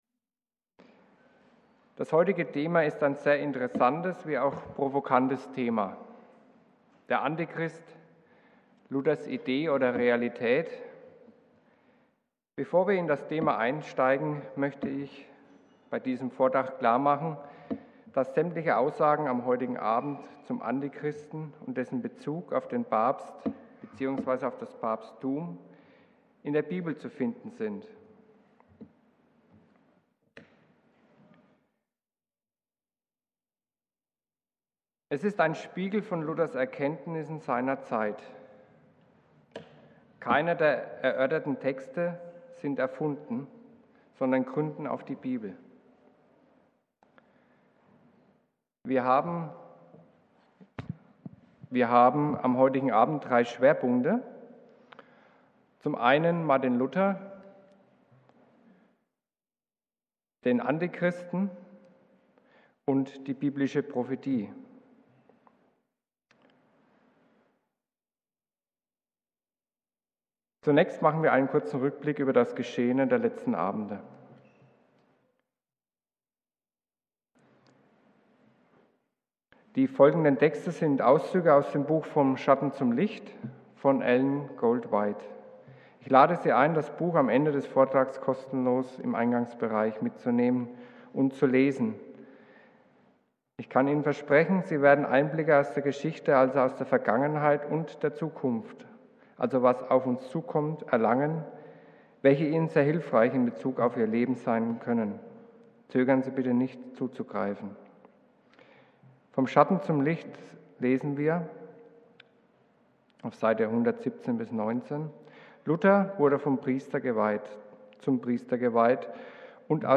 Vorträge